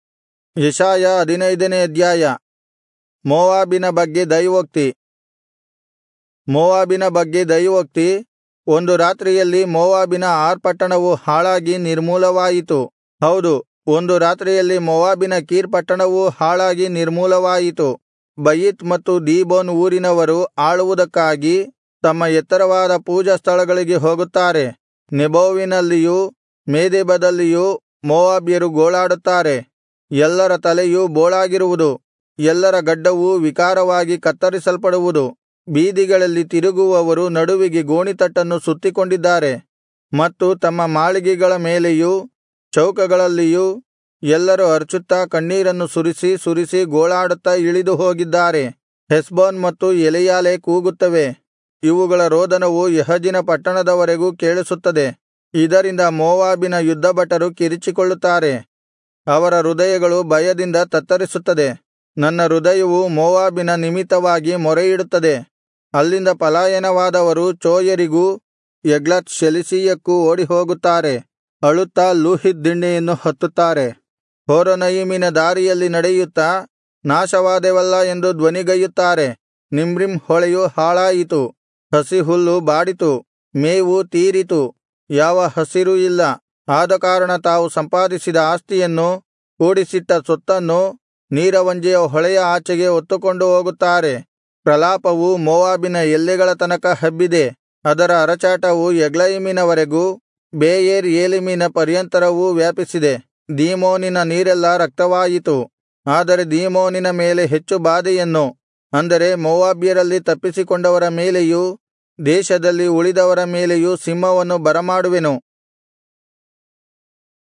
Kannada Audio Bible - Isaiah 34 in Irvkn bible version